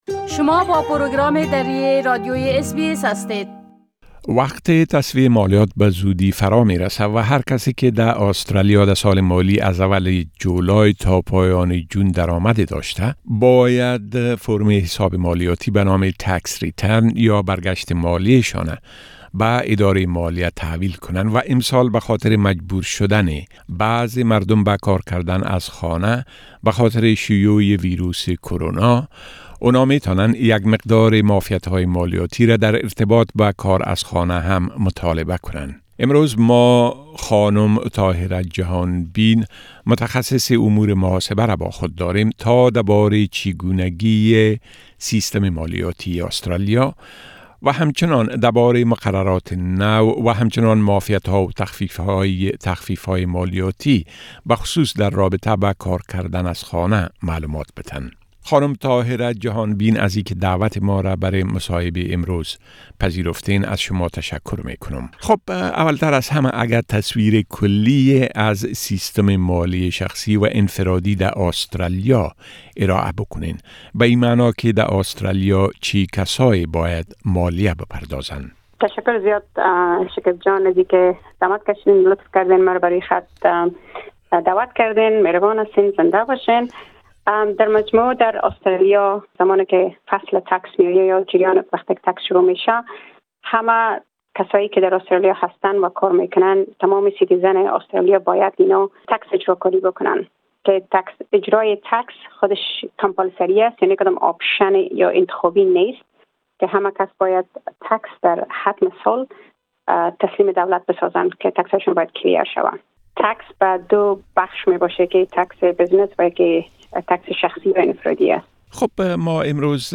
مصاحبه اس بی اس دری